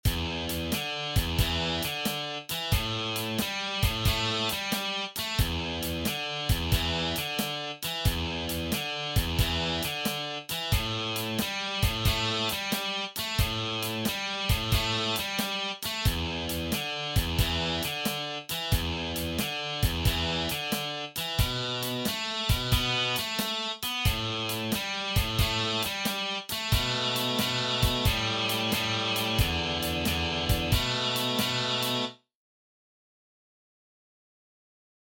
ein Rock/Blues-typischer 12-Takter
die Rock-/Bluesgitarre für Anfänger.